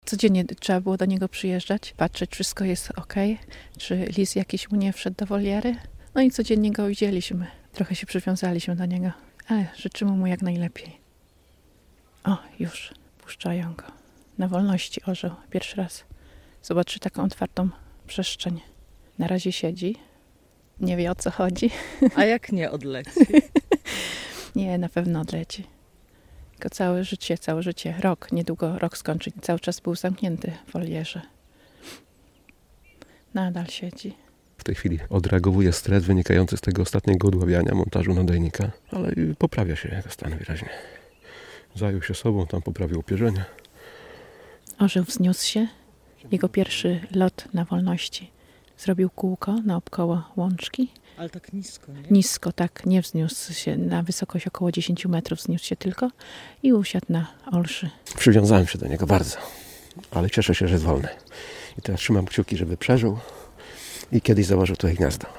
Moment wypuszczenia orła